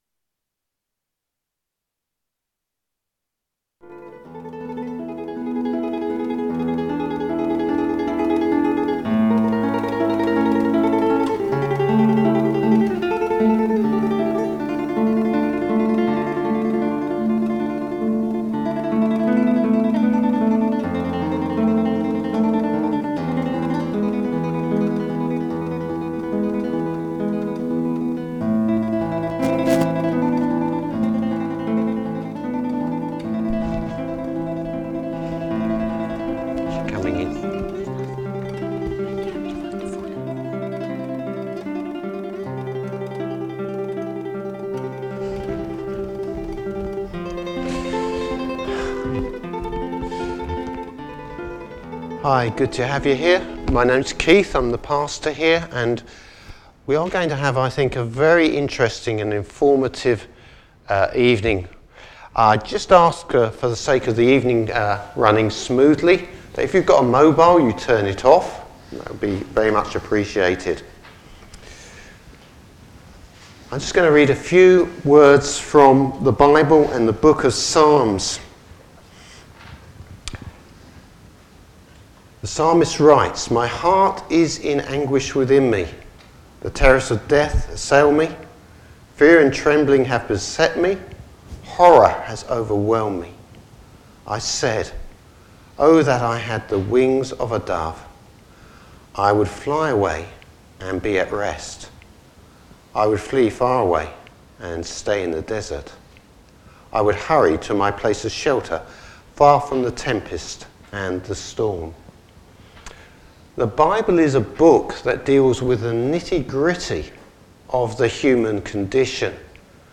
Service Type: Evangelistic Evening.